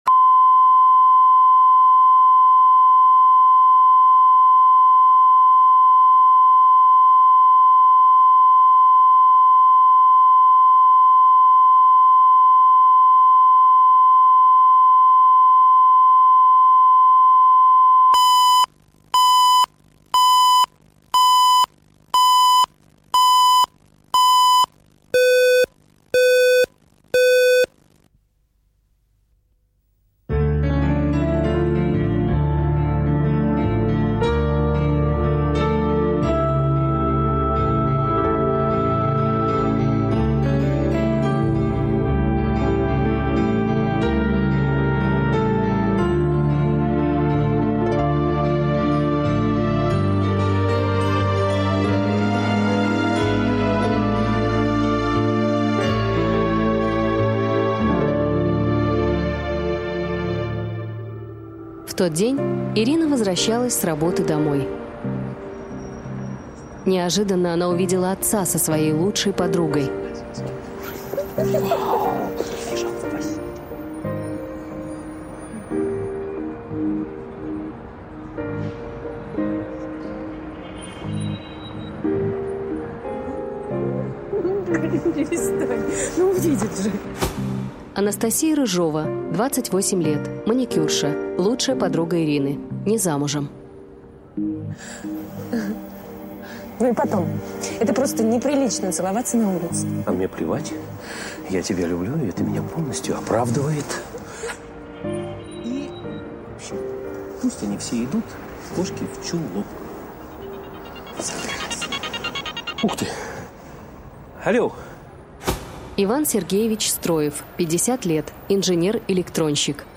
Аудиокнига Родная-неродная